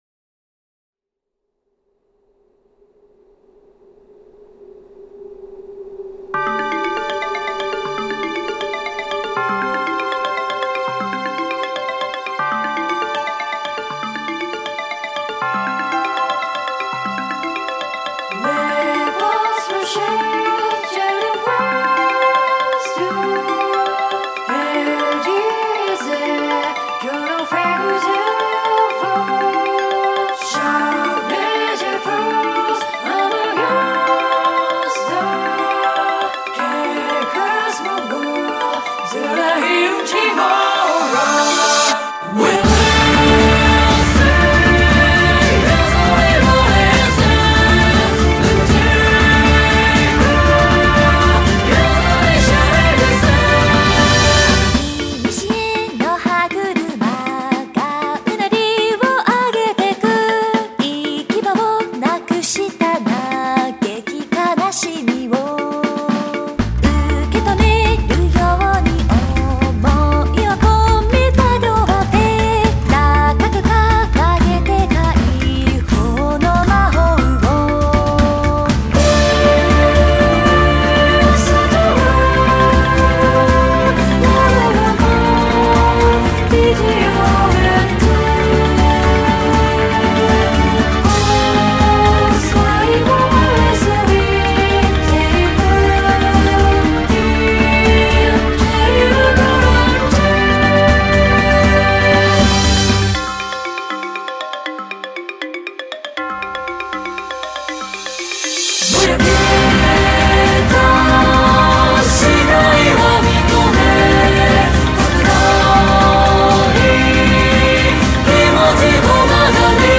10曲入り オリジナルボーカルアルバム
多重録音コーラスと民族調RPGサウンド